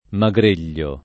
[ ma g r % l’l’o ]